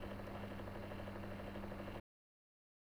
Fan2.wav